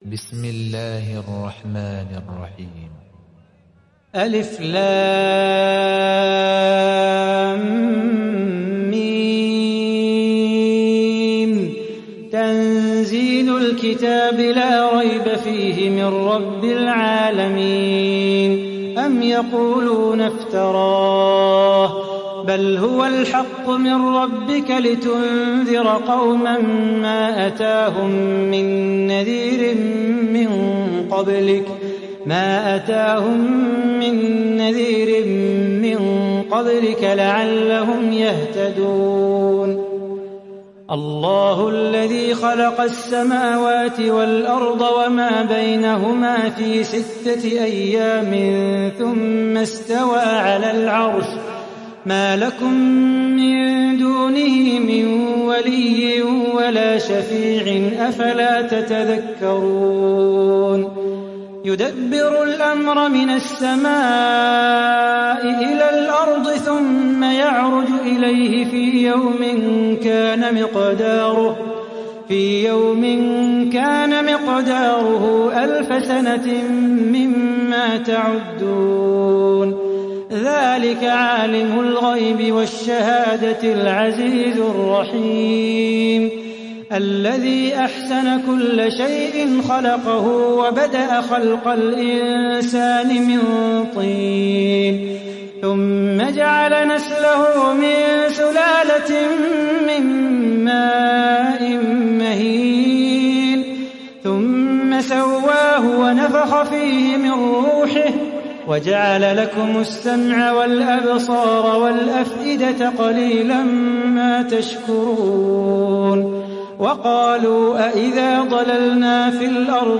Sourate As Sajdah Télécharger mp3 Salah Bukhatir Riwayat Hafs an Assim, Téléchargez le Coran et écoutez les liens directs complets mp3